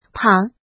pǎng
pang3.mp3